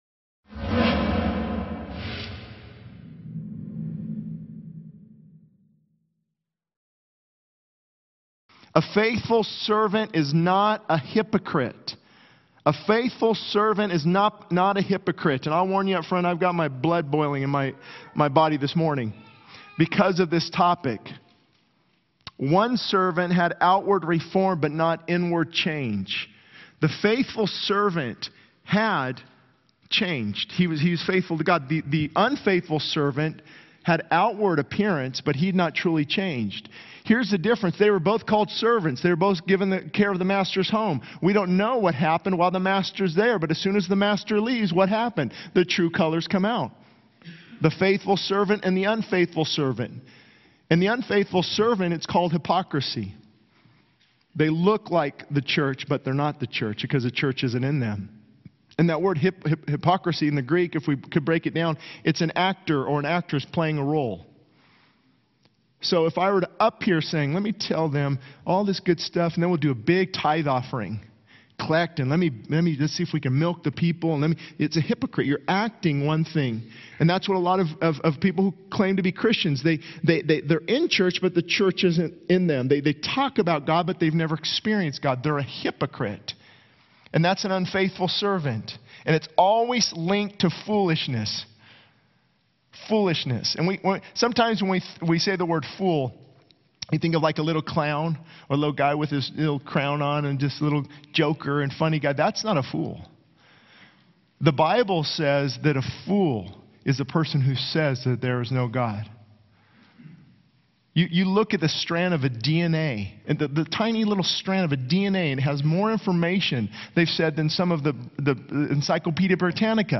He stresses the urgency of being prepared for Christ's return, as the time is short and the stakes are high. Ultimately, he challenges the congregation to reflect on whether they are wise or foolish servants in their walk with God.